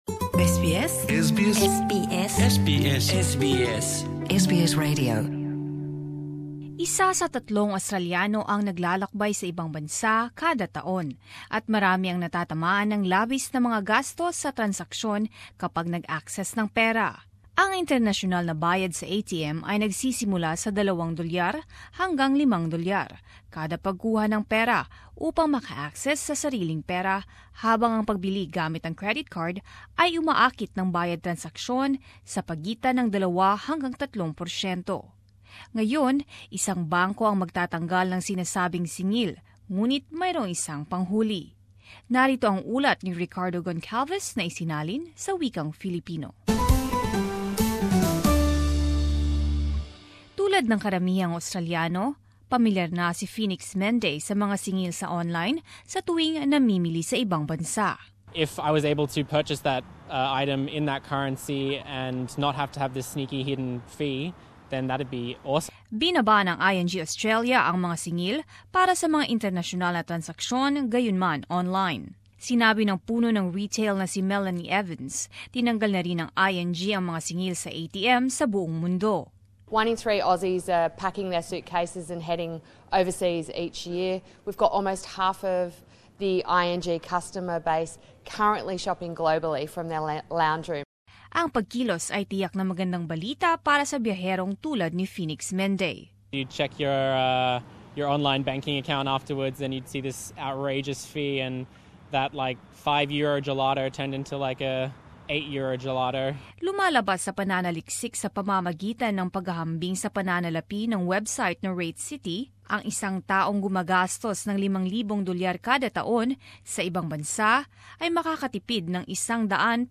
Narito ang ulat